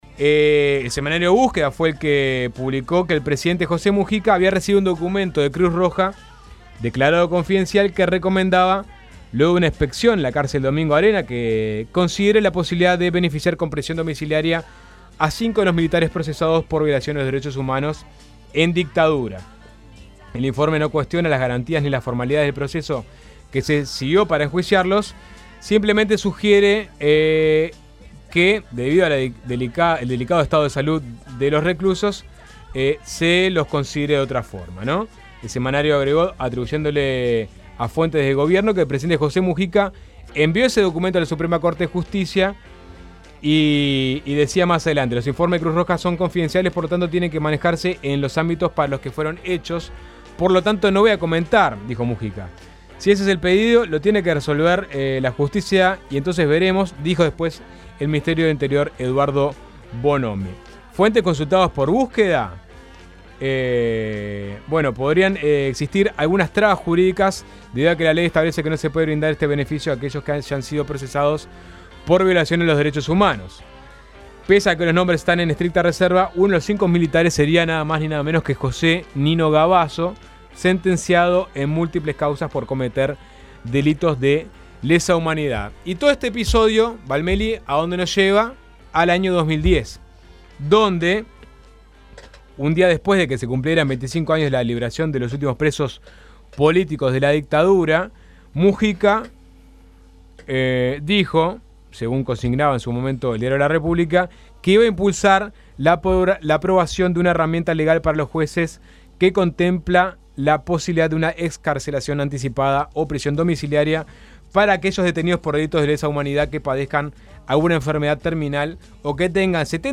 Además, leímos los mensajes de la audiencia al respecto.